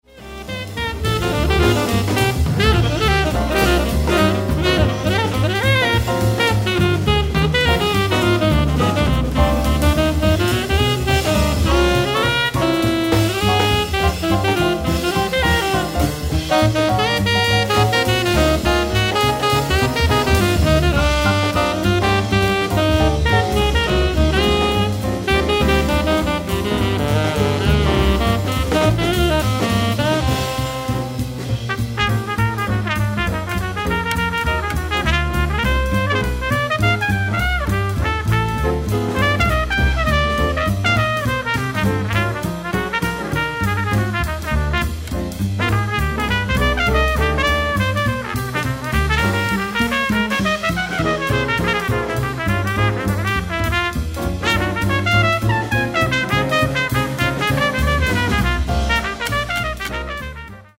tenor saxophone
acoustic bass
drums
piano
trumpet